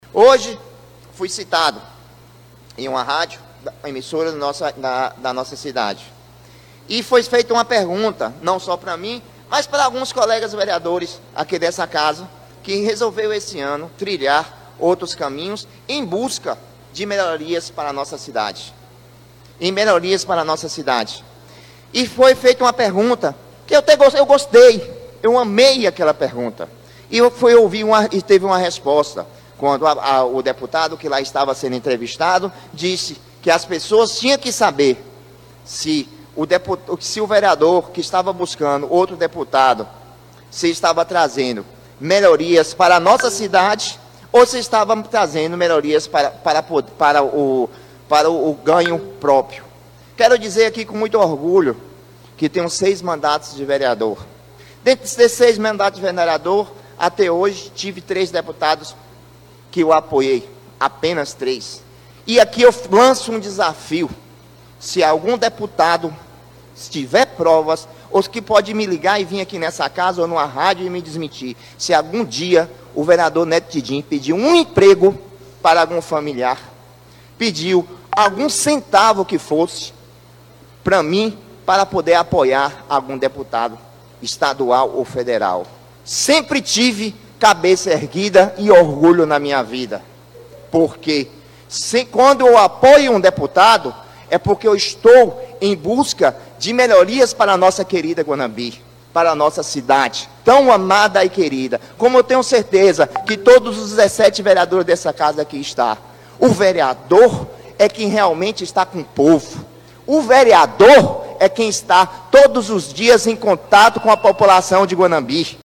Da tribuna da Câmara, o parlamentar afirmou que, ao longo de sua trajetória política, sempre manteve coerência em suas alianças.